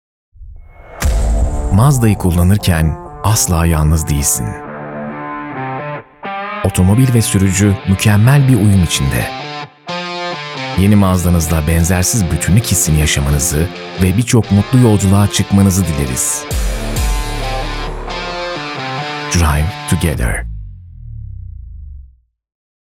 Erkek | Orta Yaş Ürün Filmi